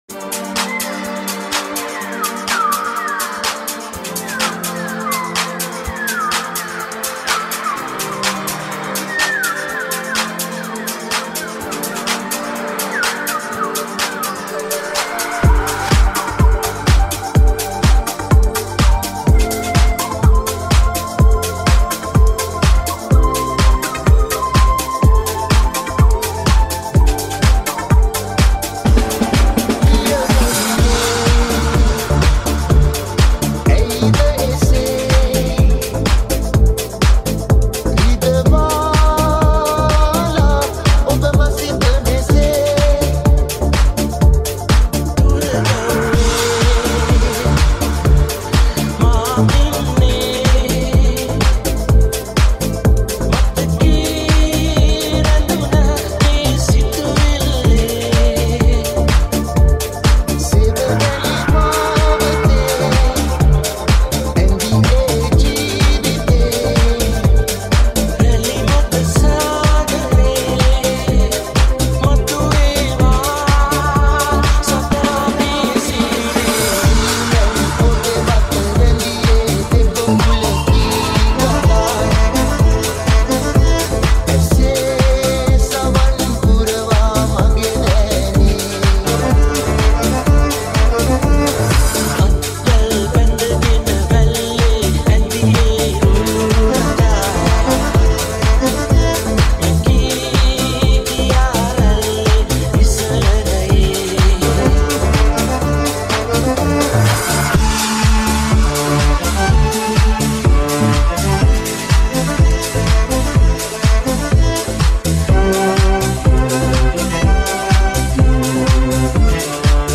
High quality Sri Lankan remix MP3 (58).